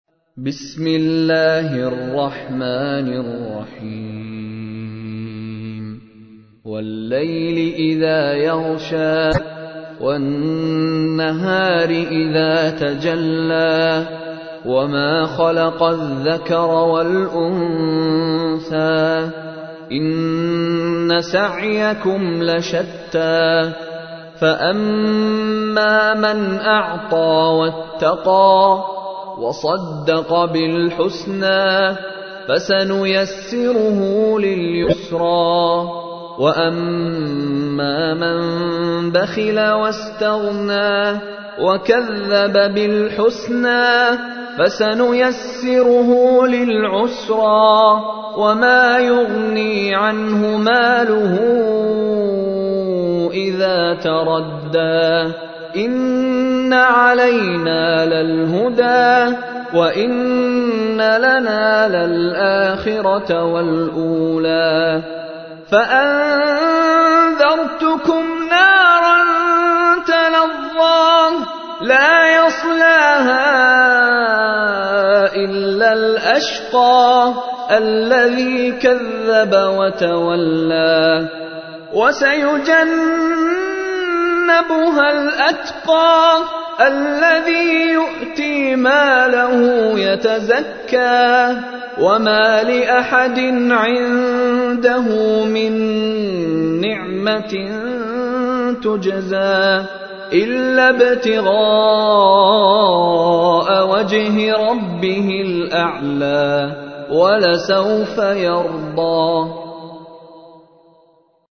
تحميل : 92. سورة الليل / القارئ مشاري راشد العفاسي / القرآن الكريم / موقع يا حسين